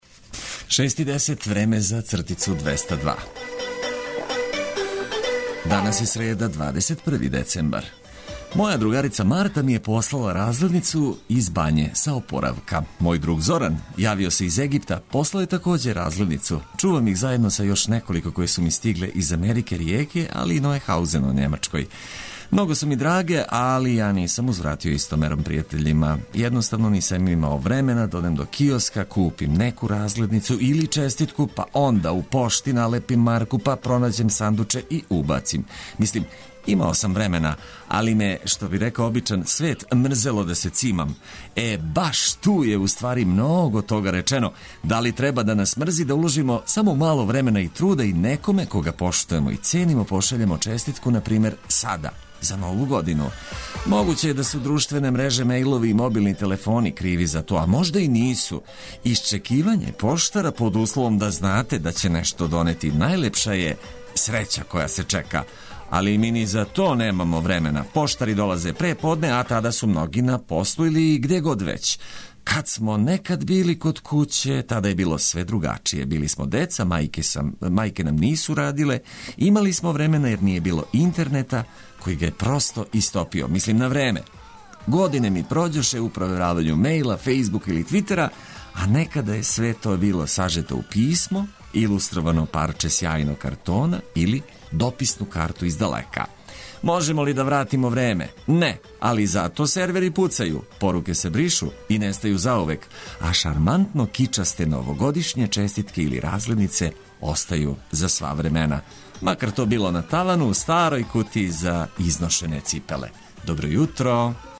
Најважније информације од користи за све који нас слушају уз брзи ритам за лакше и пријатније буђење.